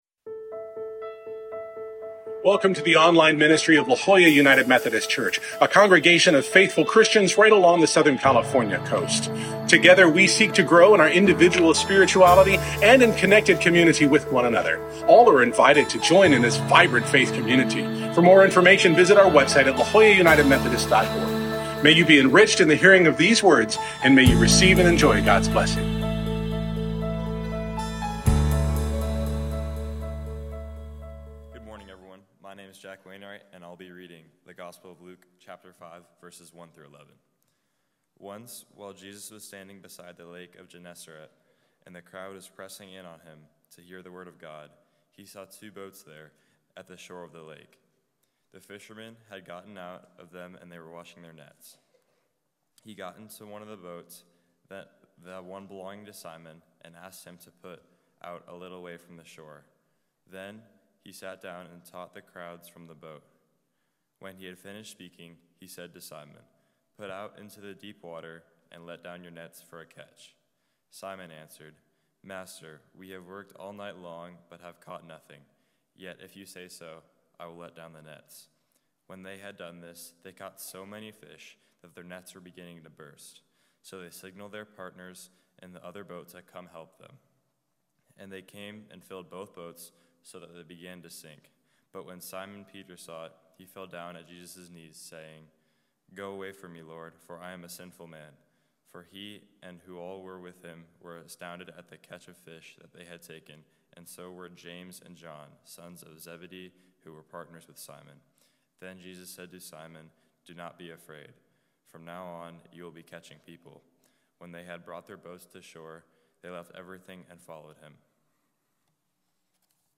This week’s sermon, part of our Foundations & Futures series, is part of our annual Scout Sunday—a day when we celebrate growth, learning, and the journey of faith. Just as scouting teaches us to see the world in new ways, Jesus invites us to go deeper, to risk trust, and to discover something greater than we imagined.